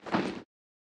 equip_generic4.ogg